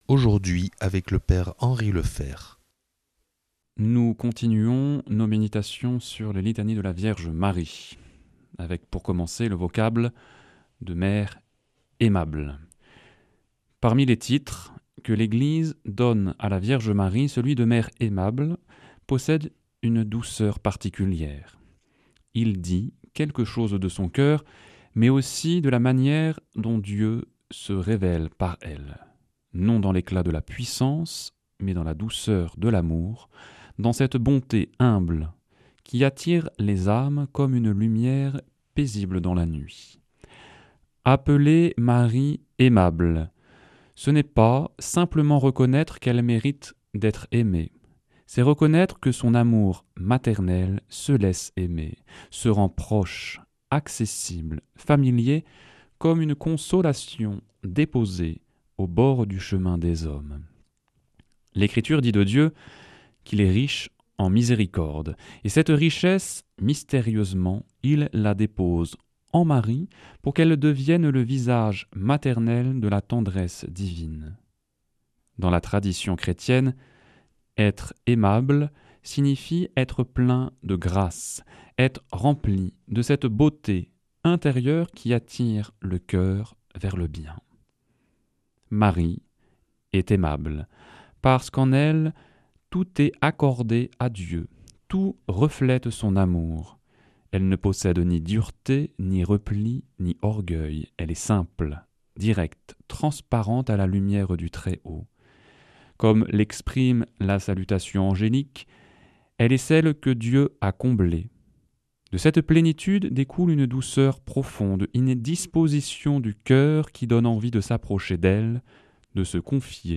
Enseignement Marial